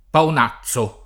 paon#ZZo] agg. — oggi raro pavonazzo [pavon#ZZo]: il pavonazzo lucente della veste talare [il pavon#ZZo lu©$nte della v$Ste tal#re] (Giotti) — antiq. pagonazzo [pagon#ZZo]: Il suo naso spugnoso e pagonazzo [il Suo n#So Spun’n’1So e ppagon#ZZo] (Magnifico)